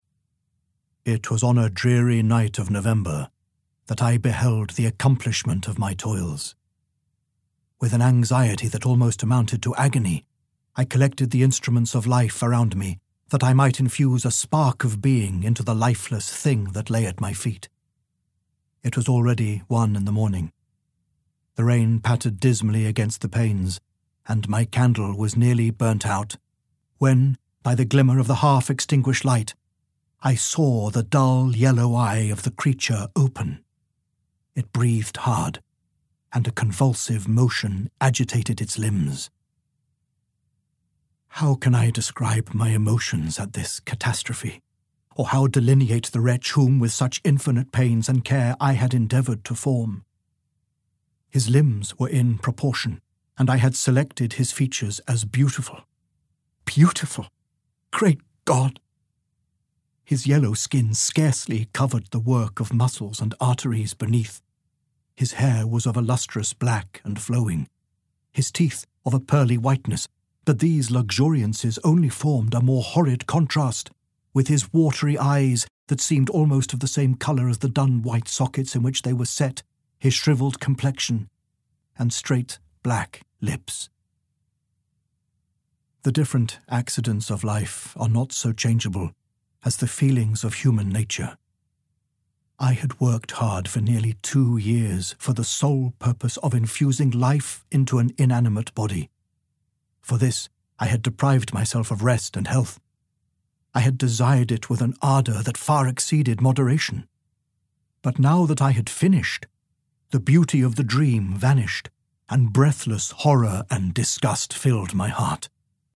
Audiobook Sample
Over thirty hours of great classic horror is brought to haunting new life by three A-list narrators whose performances are nothing short of electrifying.